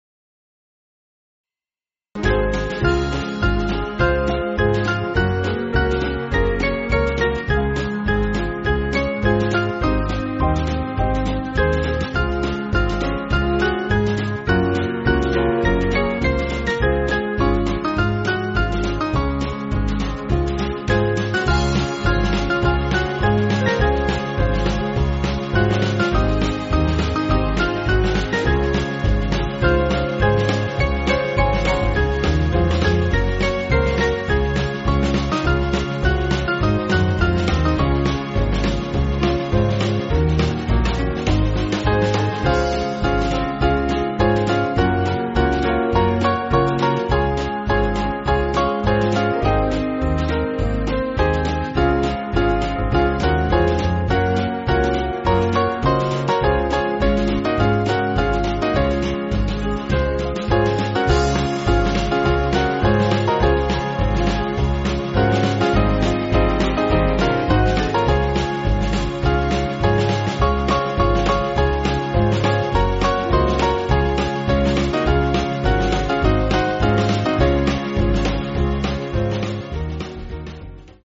Small Band
(CM)   4/Db